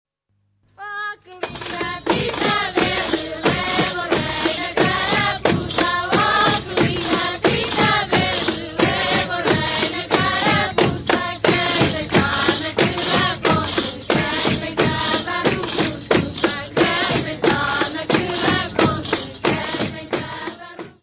Folclore português : Trás-os-Montes e Alto Douro
Grupo Folclórico Mirandês de Duas Igrejas
Maganão (Dança Paralela).